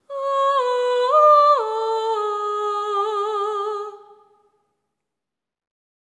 ETHEREAL10-L.wav